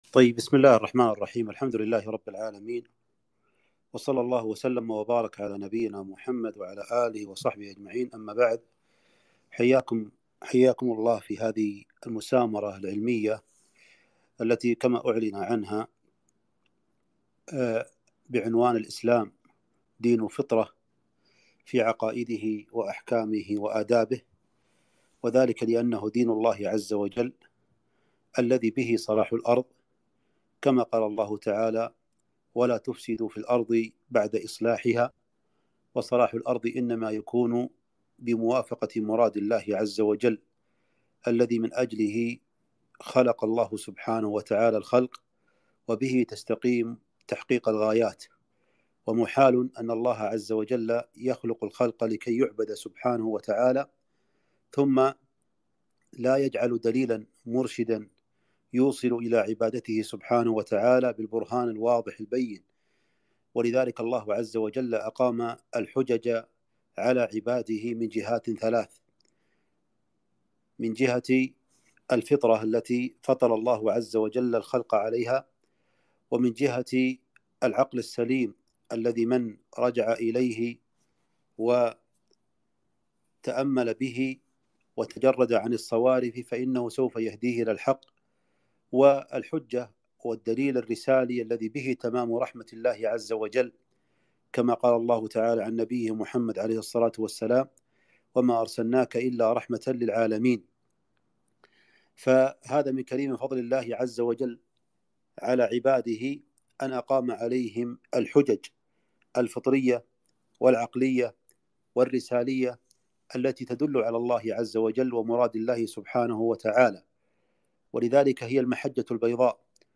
كلمة - (الإسلام دين فطرة في عقائده وأحكامه وآدابه)